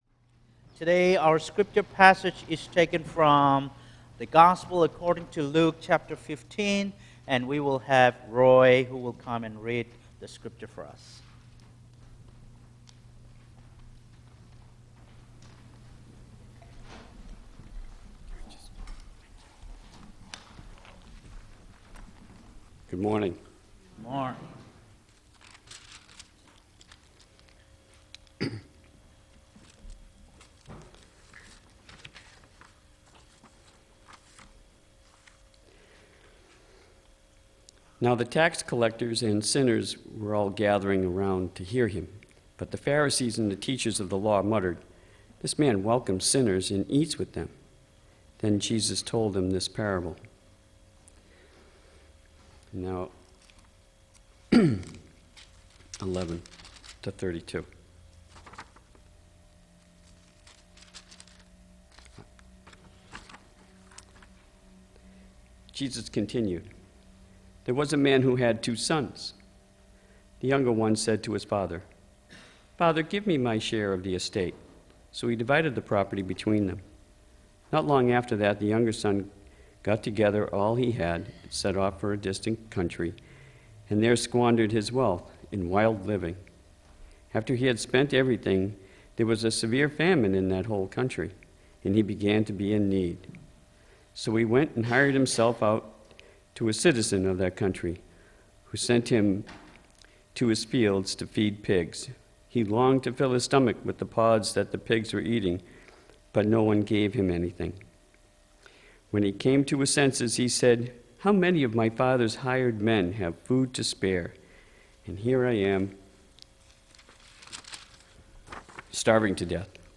Audio Recording Of Mar. 6 Worship Service – Now Available
The audio recording of our latest Worship Service is now available.